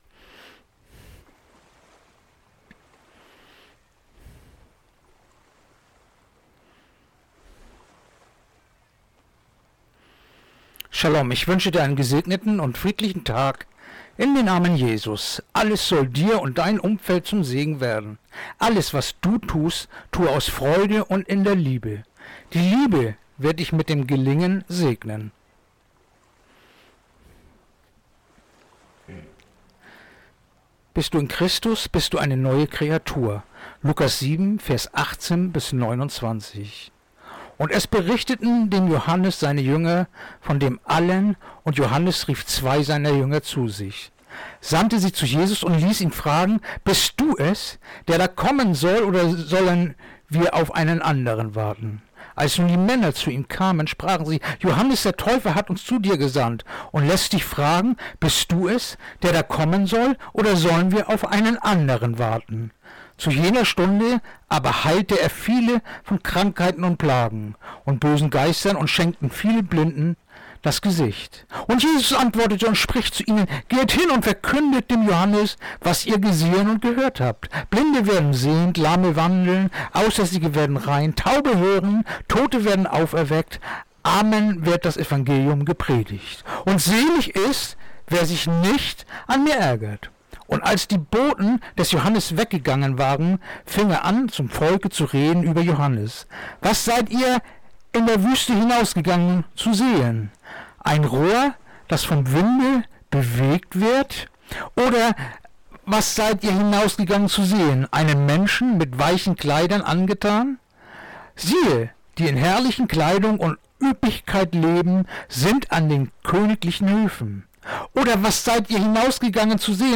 Andacht-vom-04.-Maerz-Lukas-7-18-29